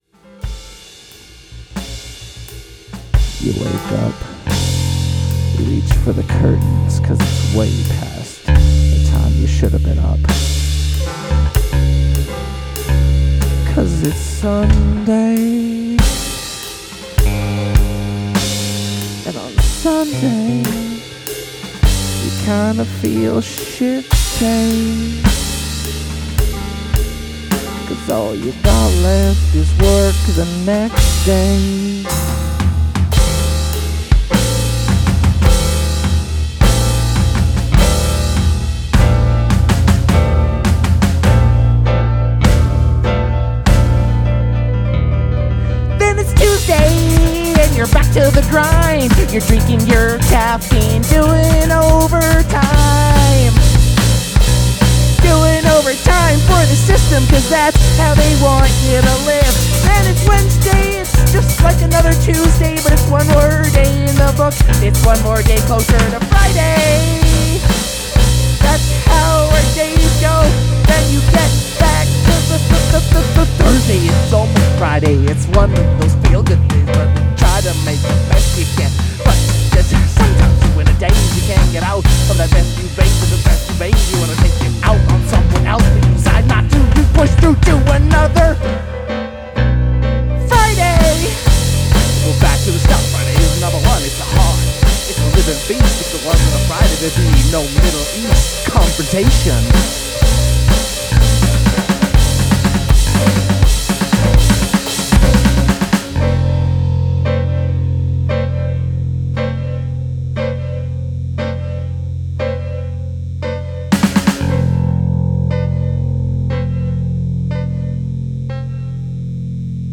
Experimental Rock
Main Vocals
Drums
Piano
Bass Guitar